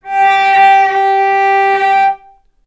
Strings / cello / sul-ponticello
vc_sp-G4-ff.AIF